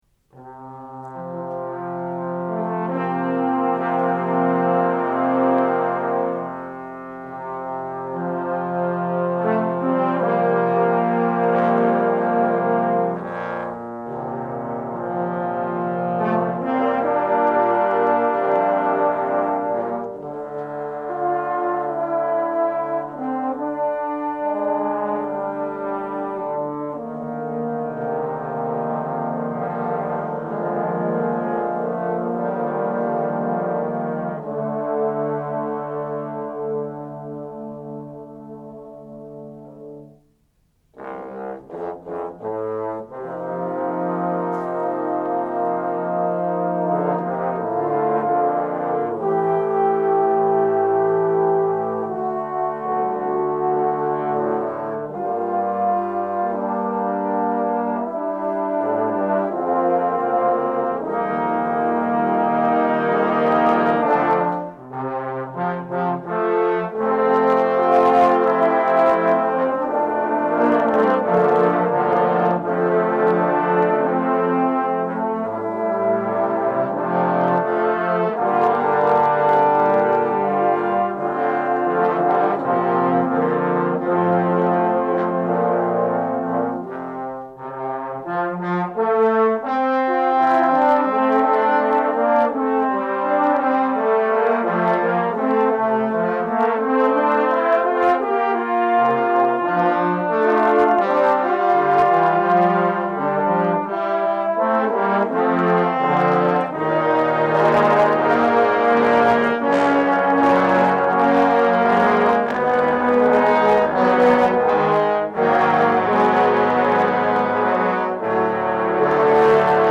For Trombone Ensemble
4 Trombones and 2 Bass Trombones.